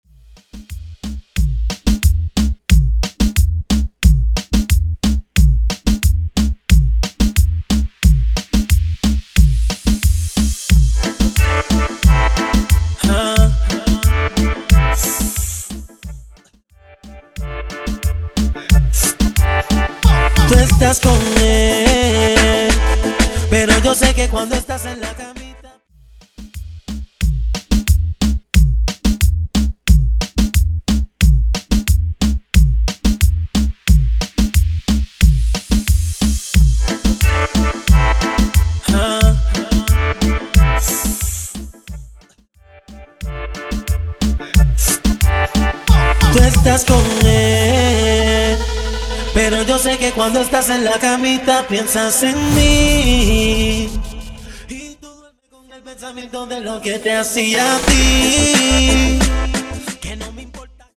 Intro Dirty, Intro Acapella Dirty